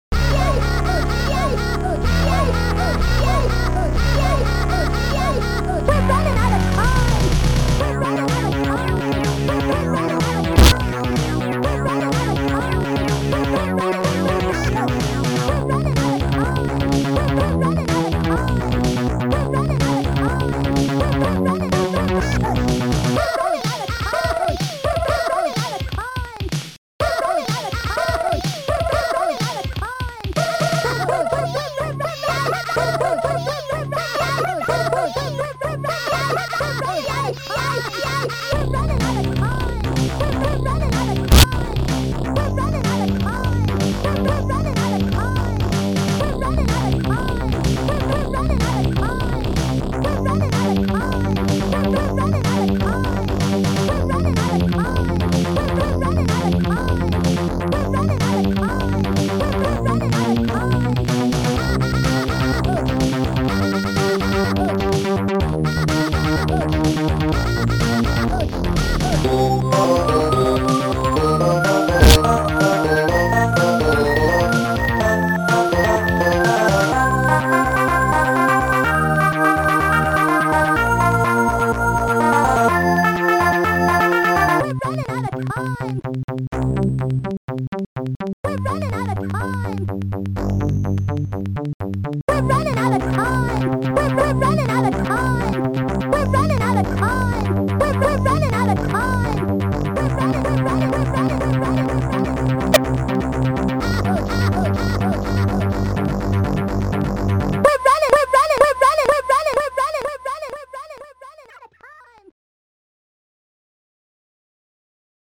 st-04:rawsynth
st-01:popsnare2
st-01:funbass
st-01:bassdrum2
st-01:techbass
st-01:voices